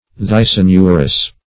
\Thys`a*nu"rous\
thysanurous.mp3